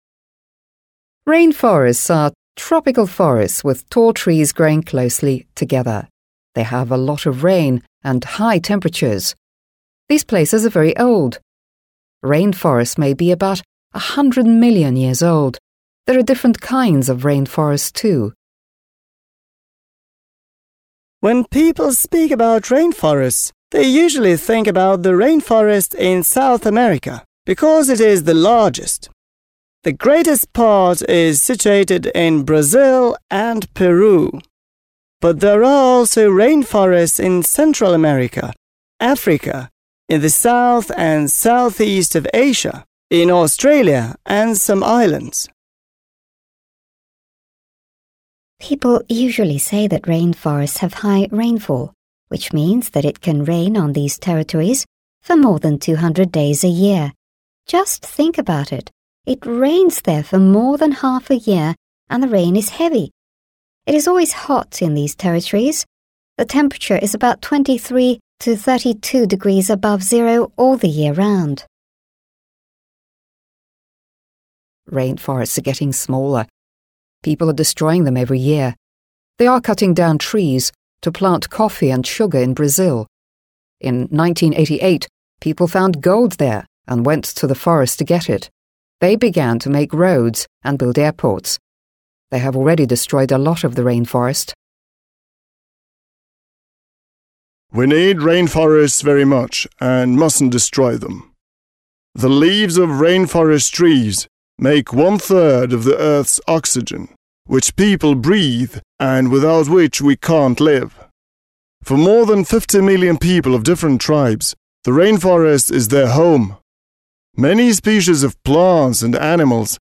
You will hear 5 people speaking about rainforests1.